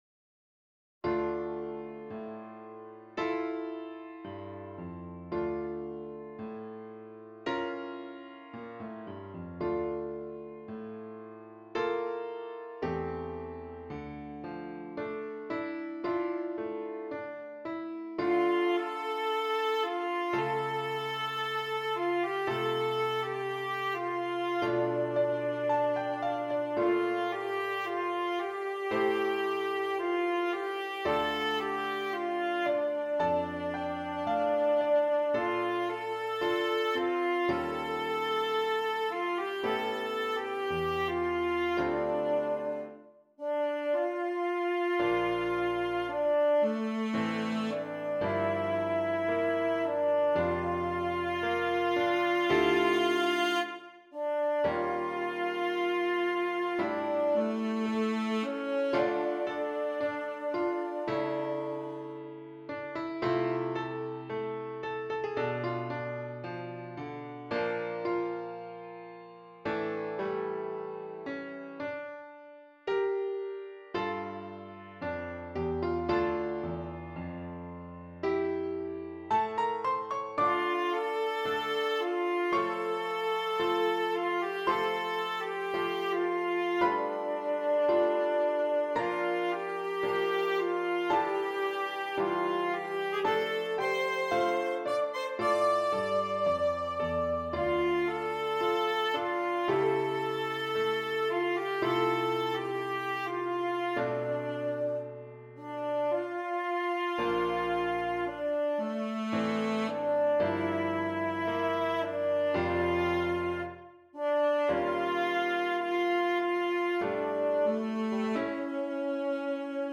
Alto Saxophone and Keyboard